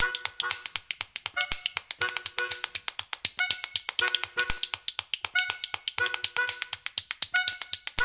Spoons